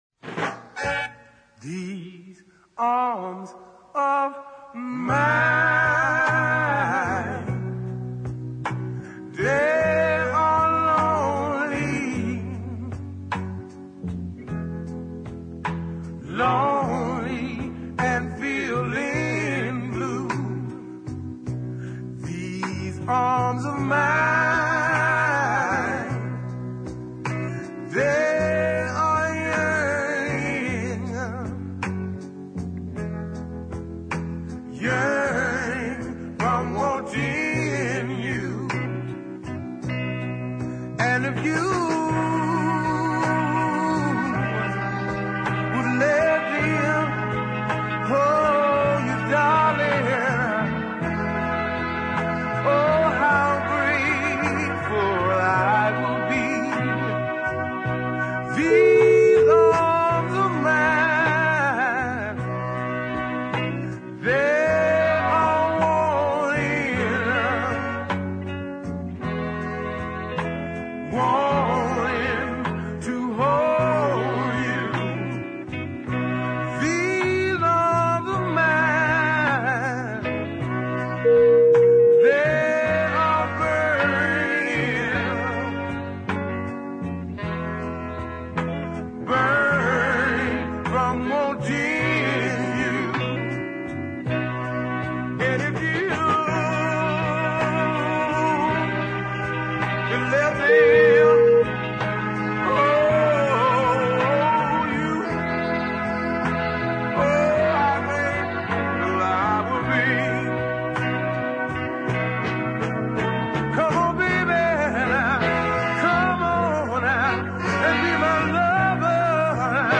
which suits the duet style of the cousins very well indeed.